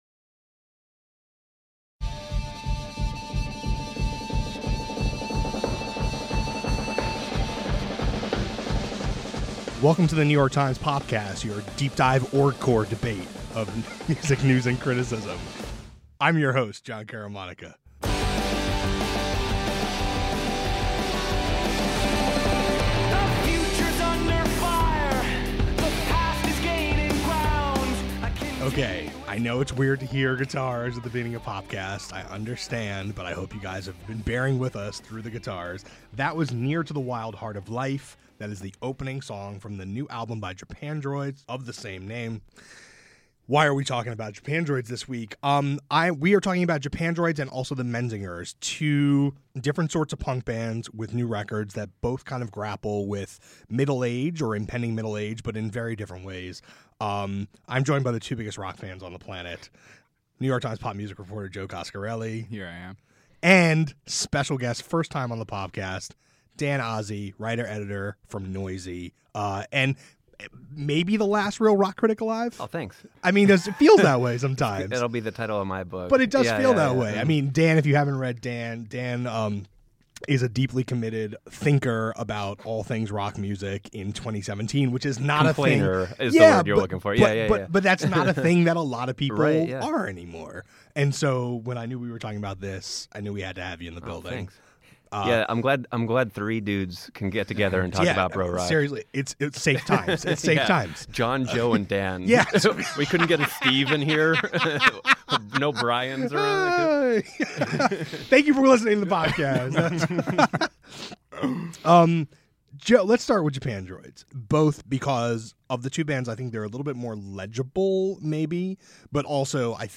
The current political climate doesn’t come up even once during the latest Popcast, a conversation about Japandroids, the Menzingers and the state of rock.